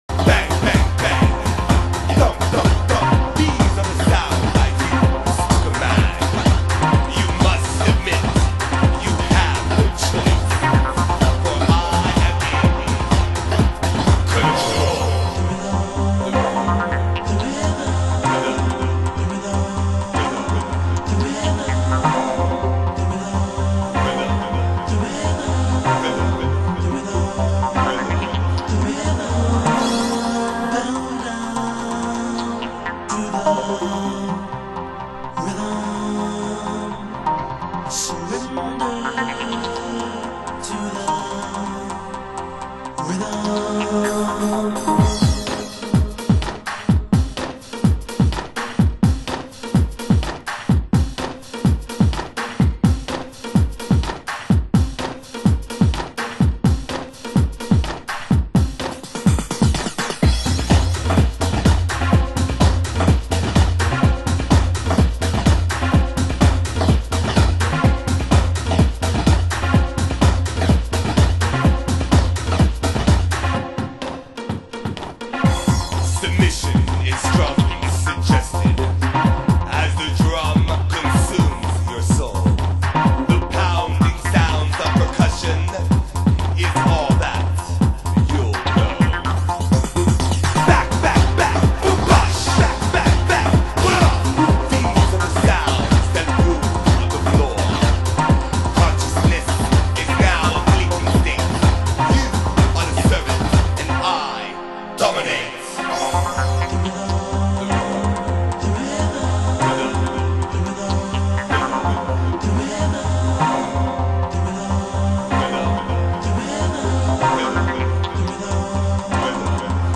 盤質：軽いスレ有/少しチリパチノイズ有　　ジャケ：良好